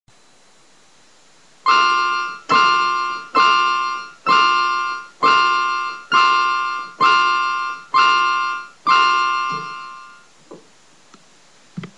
描述：我正在键盘上弹奏一个小耳朵的吓人的曲子。
Tag: 经典 耳pearcing 钢琴 吓人